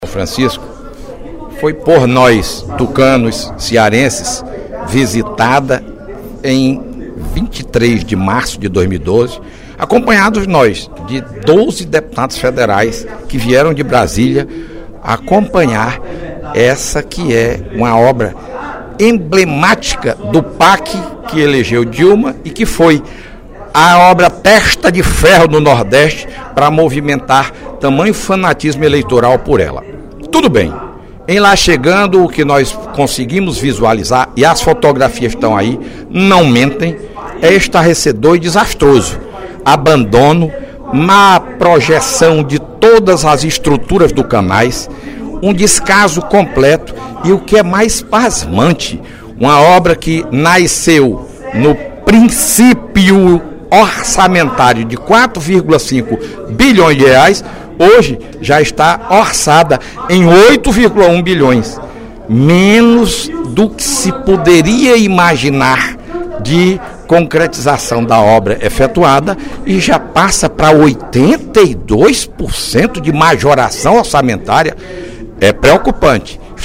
O deputado Fernando Hugo (PSDB) disse, em pronunciamento na tribuna da Assembleia Legislativa na manhã desta quarta-feira (28/03), que deputados de vários estados visitaram a obra de transposição do rio São Francisco no município de Mauriti, no último dia 23, e constataram o descaso do Governo federal com “o dinheiro do contribuinte”.